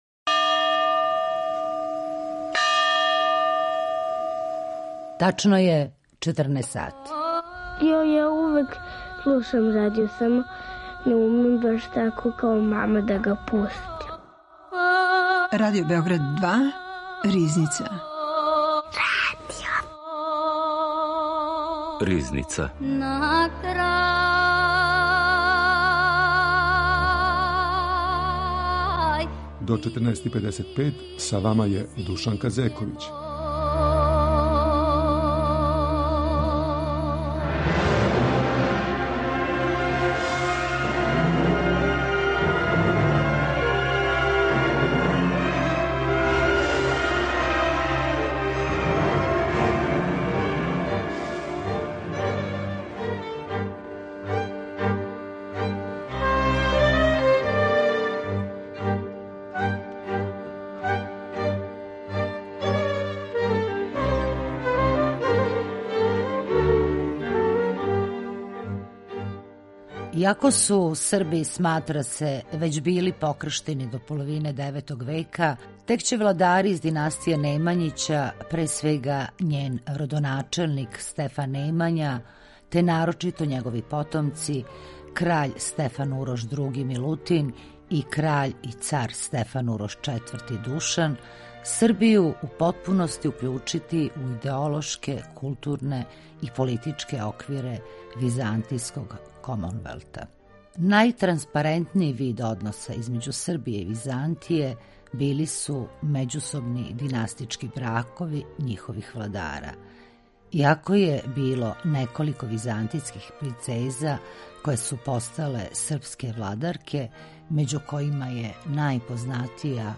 Гошћа је историчарка